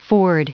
Prononciation du mot ford en anglais (fichier audio)
Prononciation du mot : ford